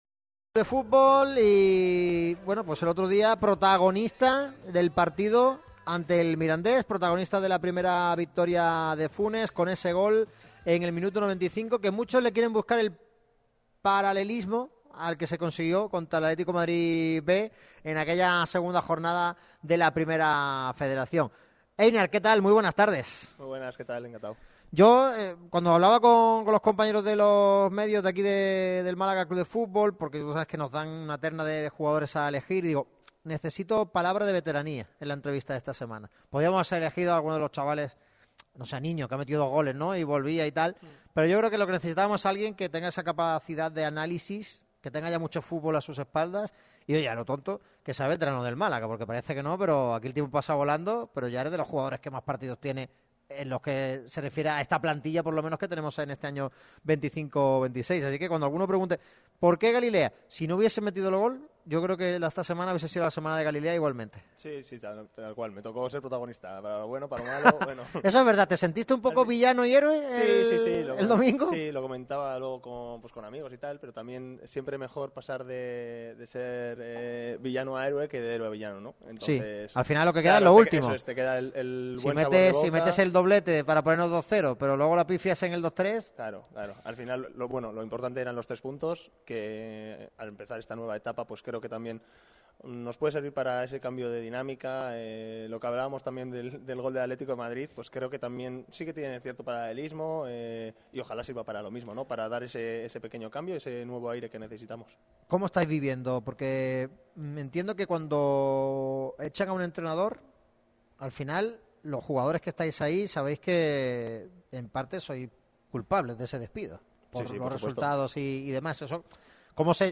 Entrevistón en Radio MARCA Málaga.
Entrevista íntegra de Einar Galilea en audio
Tras el cese de Pellicer, el anuncio de Funes y su debut con victoria ante el CD Mirandés, precisamente con gol del central vasco en el tiempo de prolongación, nos ha detallado cómo se encuentra el vestuario, su visión de los acontecimientos, vistas a futuro de la mano del técnico lojeño y mucho más. Casi una hora de entrevista imperdible.